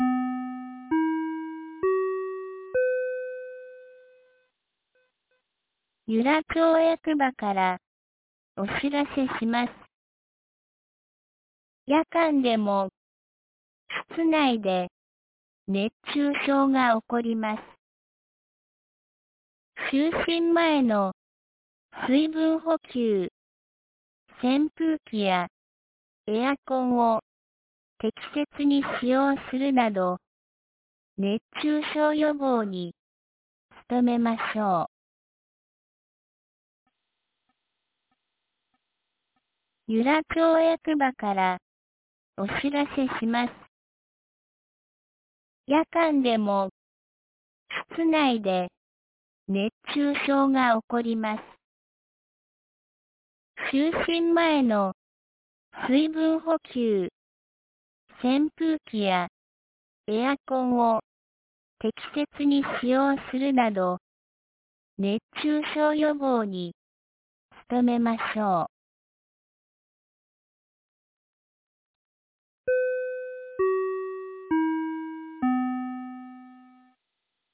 2025年08月25日 17時11分に、由良町から全地区へ放送がありました。